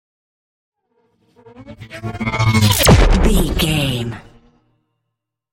Pass by fast vehicle engine explosion sci fi
Sound Effects
futuristic
intense